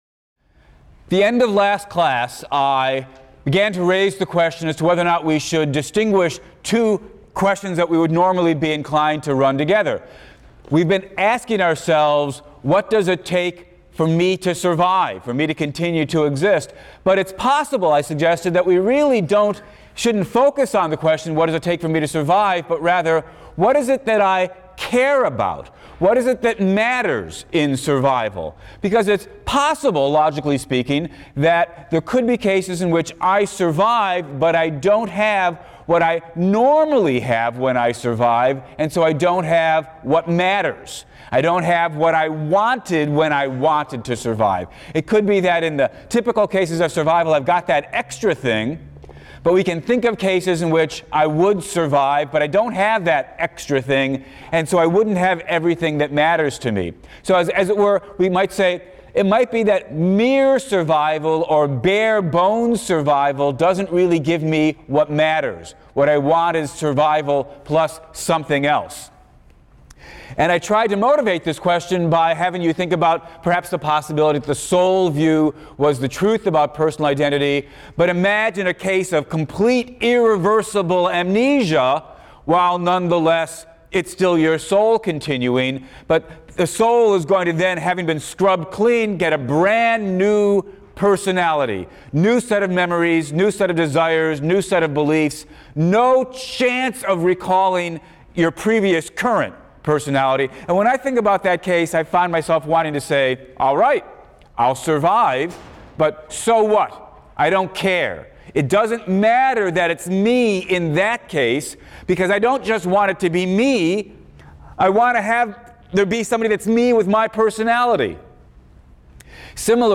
PHIL 176 - Lecture 14 - What Matters (cont.); The Nature of Death, Part I | Open Yale Courses